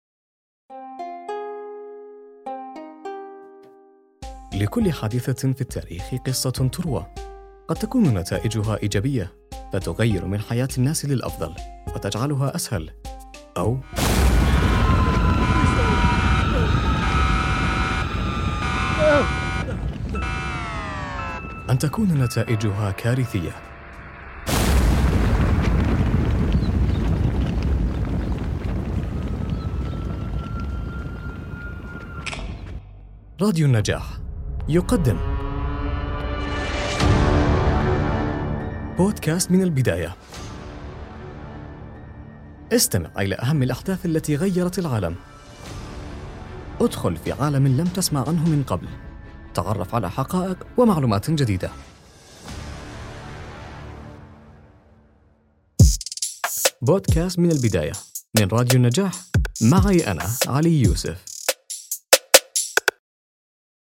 مقطع تشويقي بودكاست من البداية